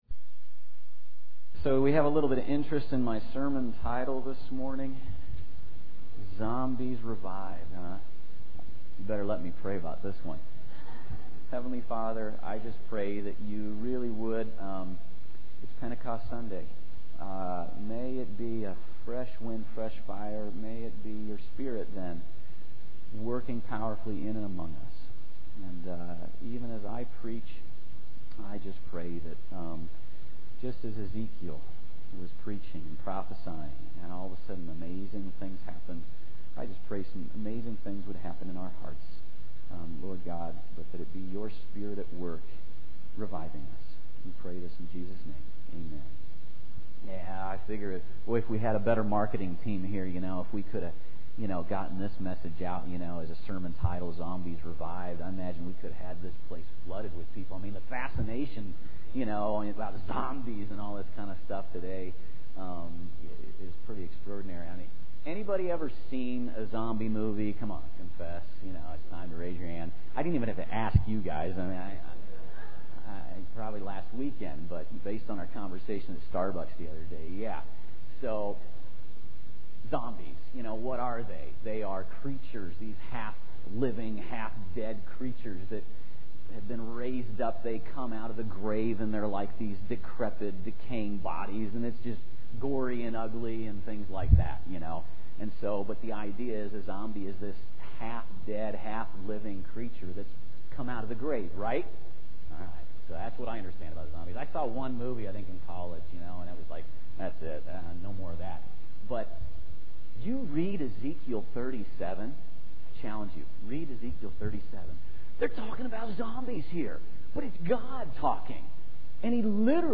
Sermons Bookmark the permalink